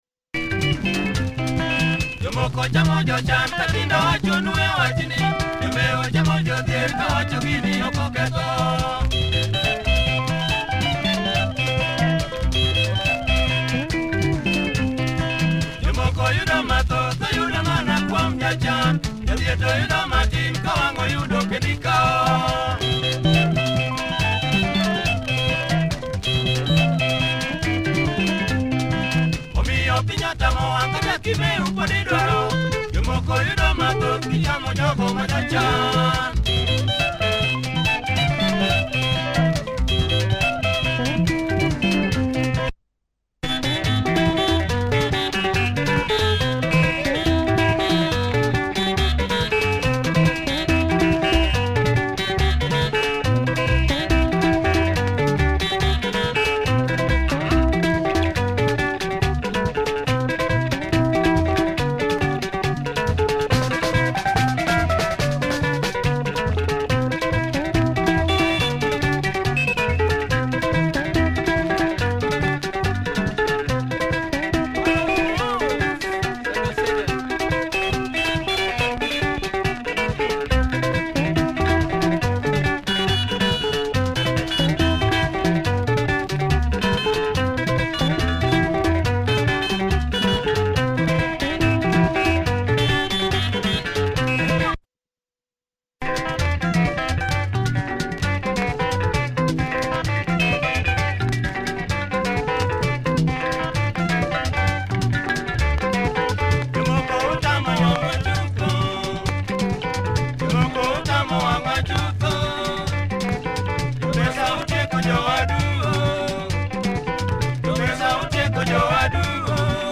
Heavy luo benga by this great group